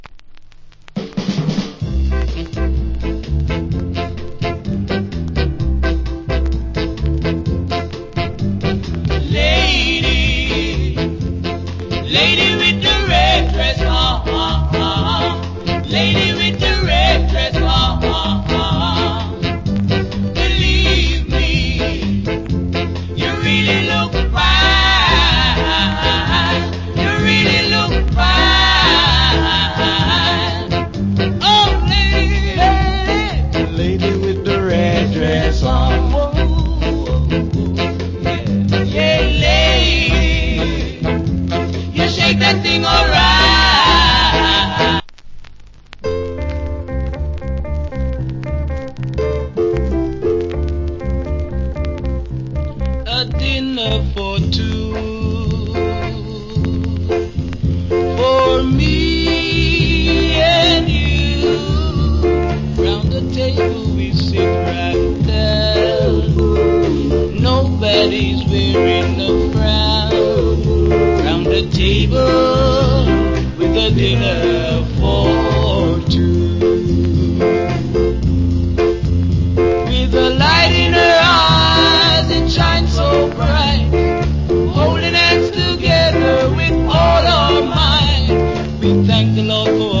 Good Ska Vocal. / Nice Ballad Vocal.